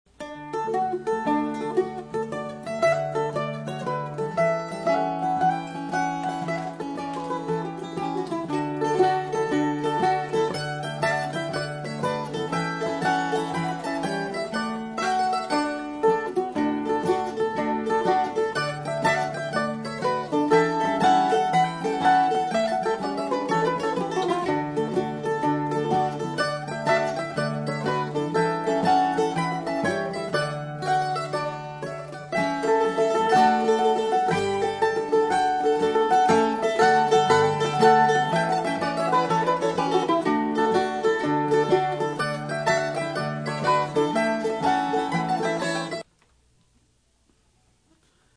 Lead Mandolin
Harmony Mandolin
Guitar